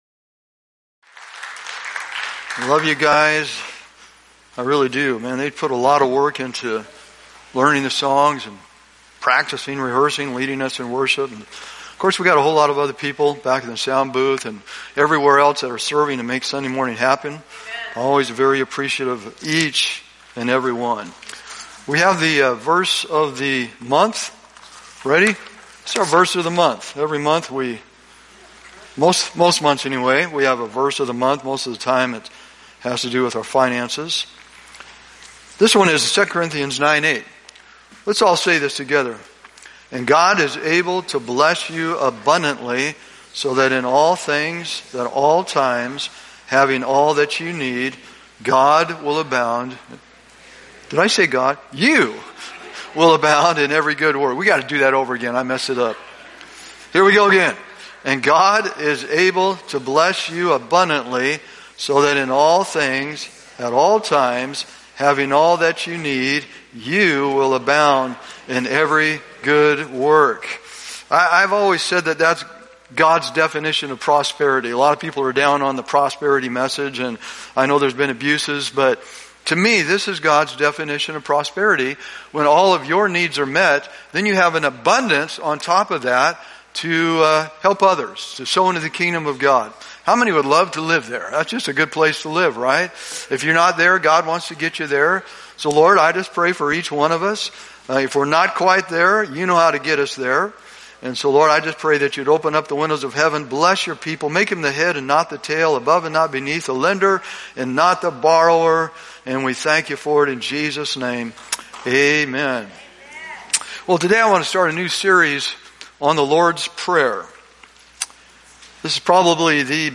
Download Download Reference Matthew 6:9 NIV Sermon Notes THE LORD’S PRAYER part 1.pdf The Lord's Prayer Part 1 The Person of prayer. 1.